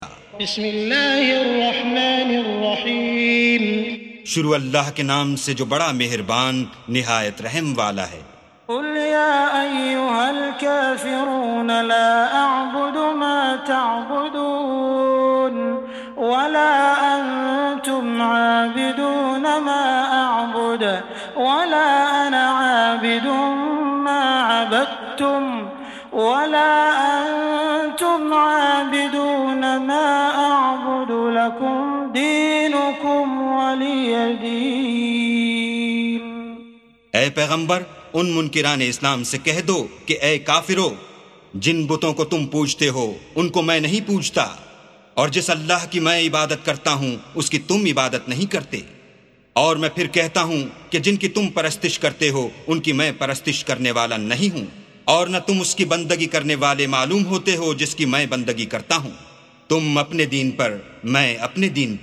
سُورَةُ الكَافِرُونَ بصوت الشيخ السديس والشريم مترجم إلى الاردو